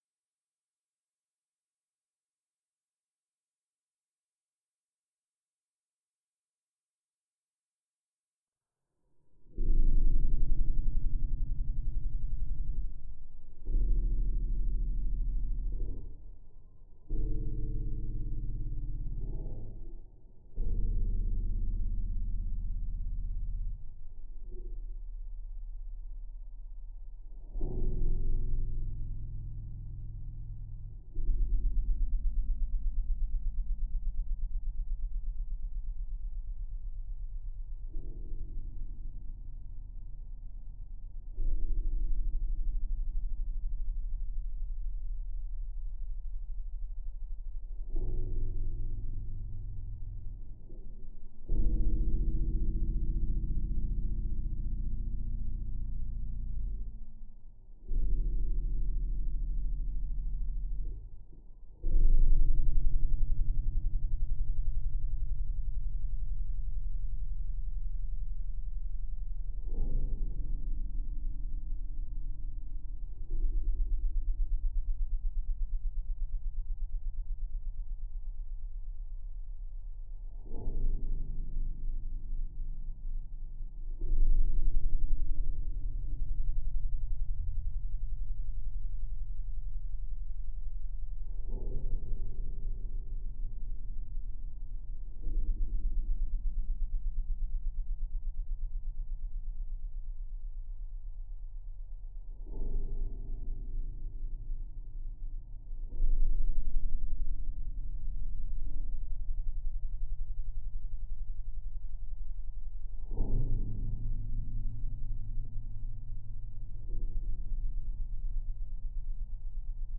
Il suono si dilata per 40 minuti, rendendo il brano incomprensibile e creando un’atmosfera disumana.